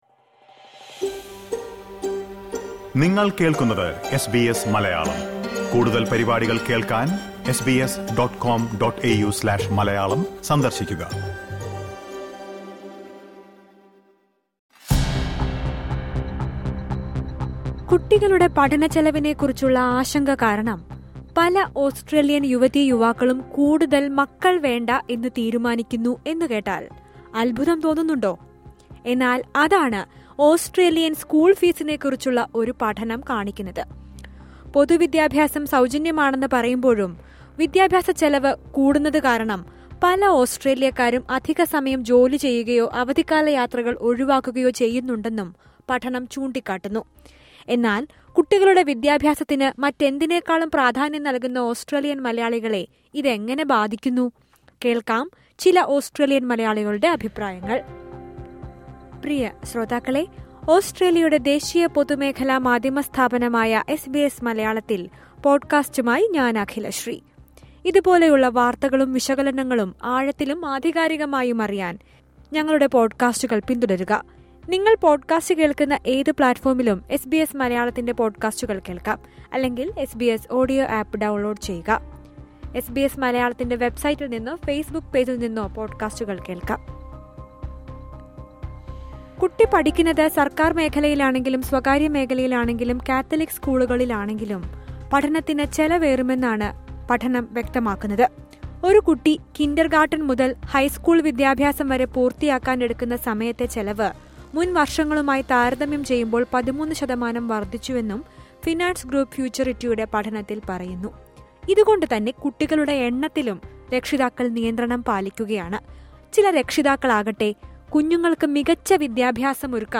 ഇത് എങ്ങനെ ബാധിക്കുന്നുവെന്ന് ചില മലയാളികൾ പ്രതികരിക്കുന്നത് കേൾക്കാം.